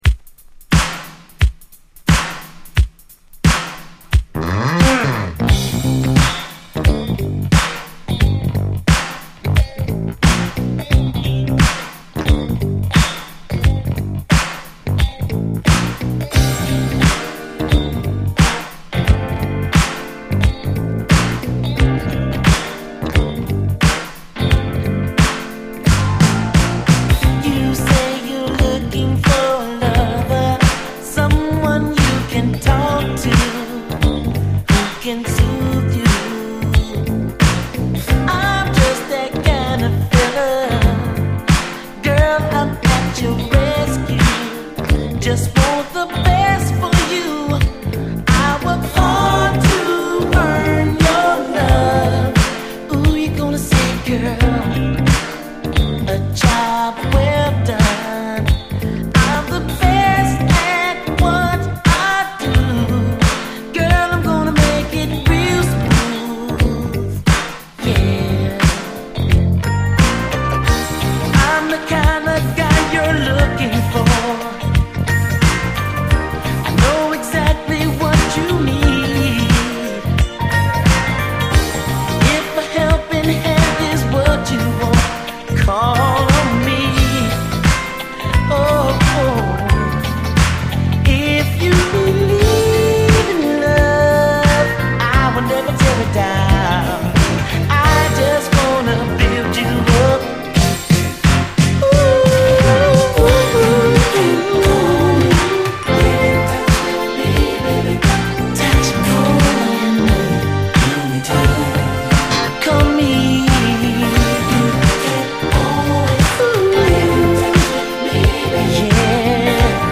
ネットリと女性ヴォーカルが絡みつくメロウなグレイト・ヴァージョン！
ラテン調のジャズ・ファンク